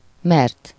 Ääntäminen
Synonyymit parce que autocar Ääntäminen France (Paris): IPA: [kaʁ] Haettu sana löytyi näillä lähdekielillä: ranska Käännös Ääninäyte 1. mert 2. mivel 3. ugyanis Suku: m .